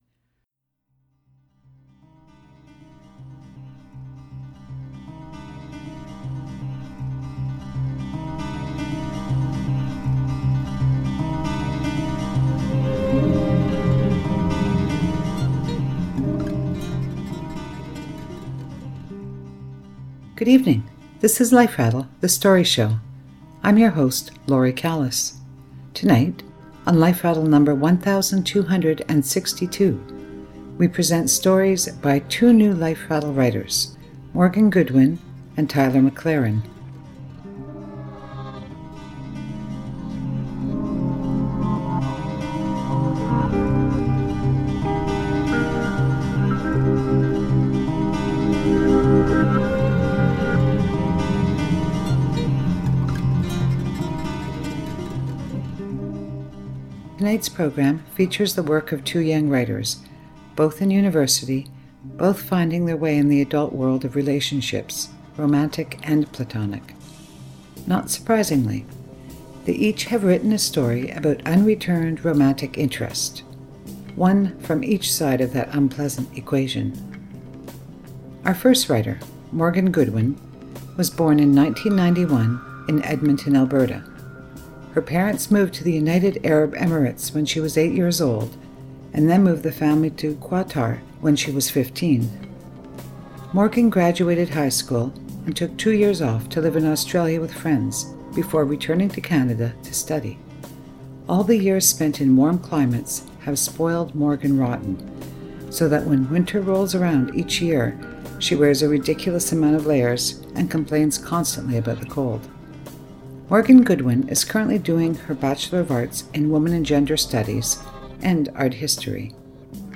On tonight’s program we present the work of two young writers, both in university, both finding their way in the adult world of relationships, romantic and platonic. Not surprisingly, they each have written a story about unreturned romantic interest, one from each side of that unpleasant equation.